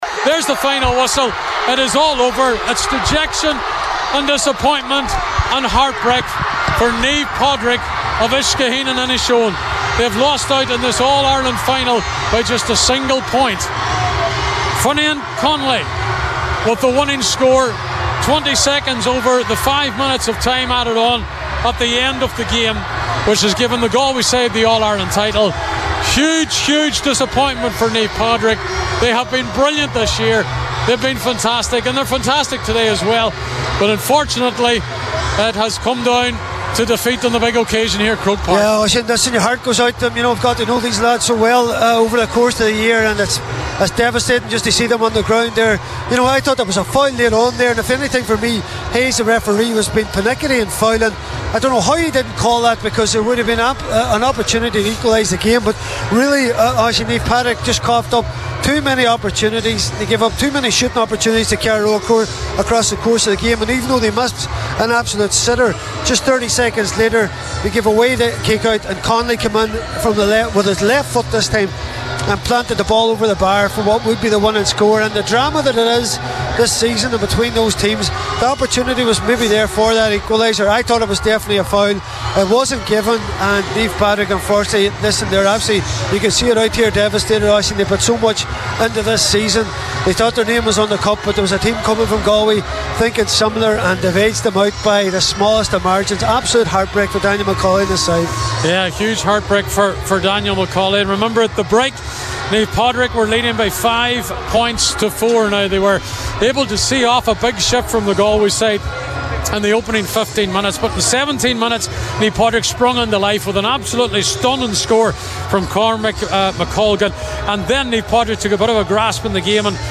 were at GAA Headquarters for Highland Radio Saturday Sport…